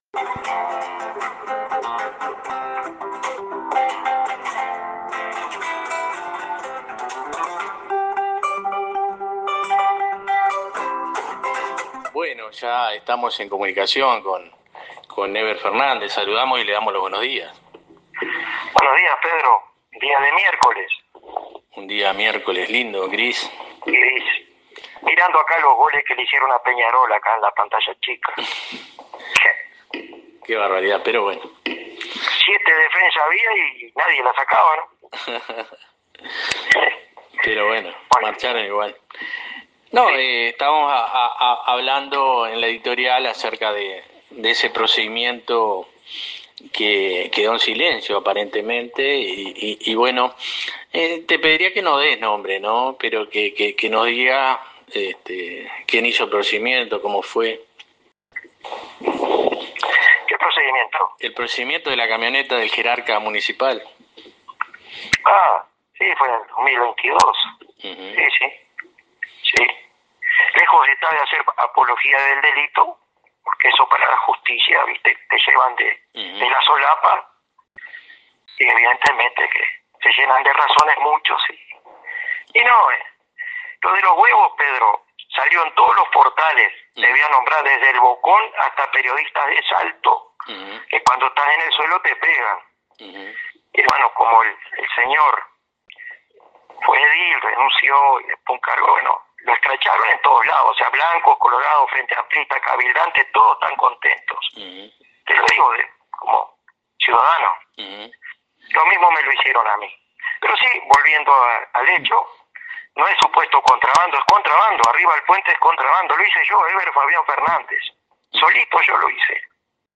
AQUÍ LA ENTREVISTA COMPLETA